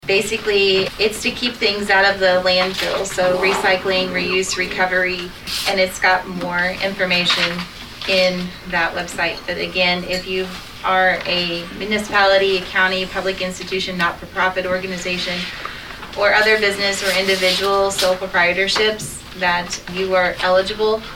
Saline County Northern District Commissioner Stephanie Gooden announced there is a grant opportunity available for some entities during the commission meeting on Thursday, February 4.
Gooden explained more.